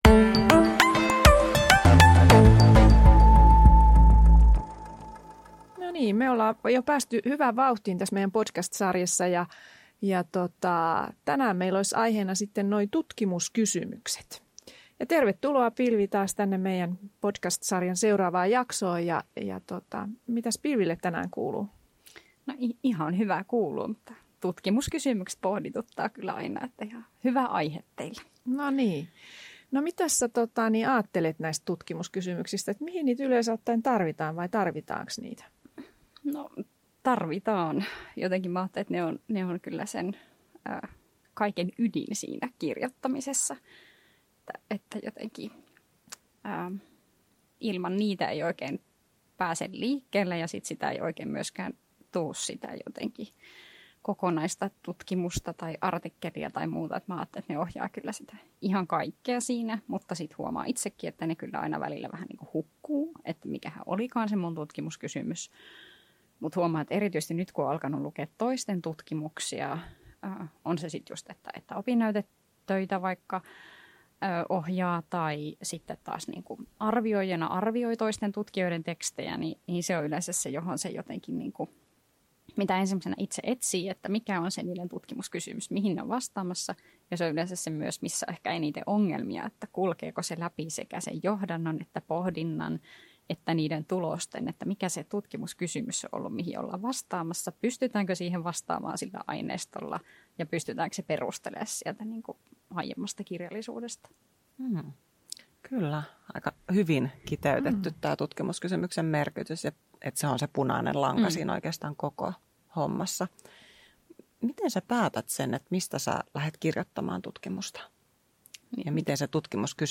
Podcast Tutkimuksen kirjoittamisesta, Tutkimuskysymykset